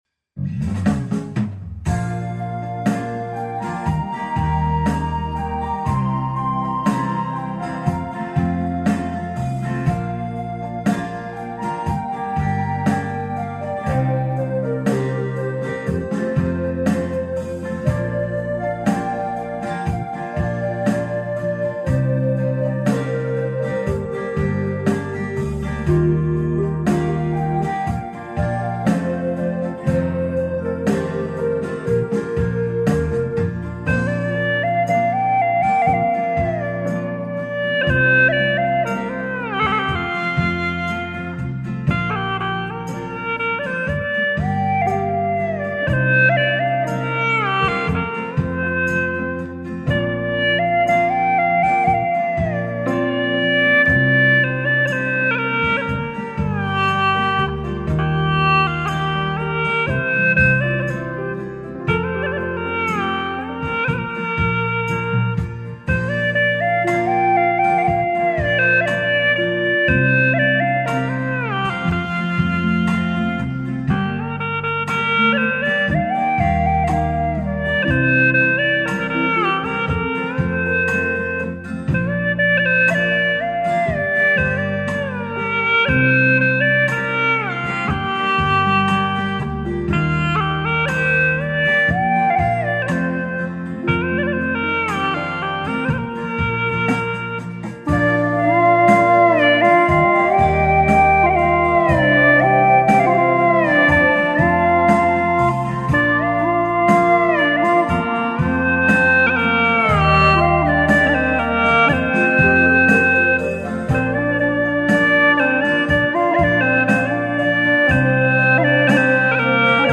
调式 : 降B 曲类 : 独奏
应丝友们的要求，录了一个我演奏的版本，大小降B合奏，供大家参考。